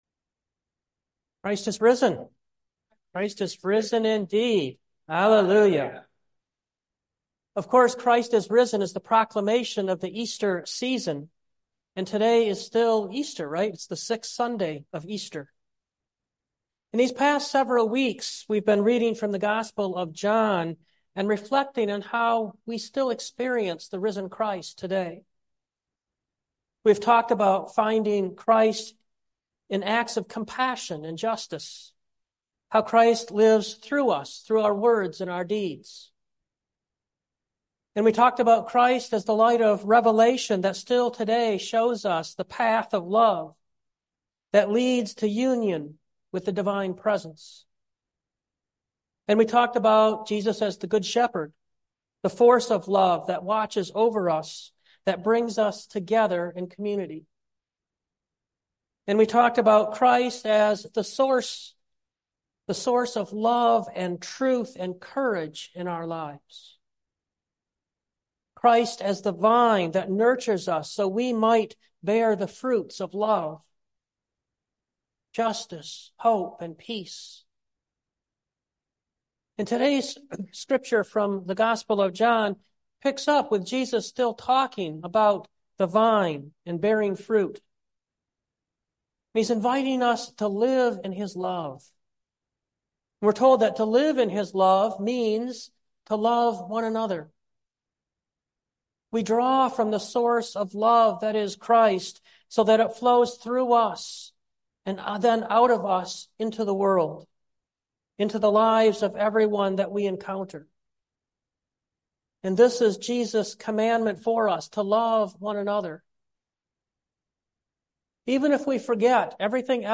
2024 Love One Another Preacher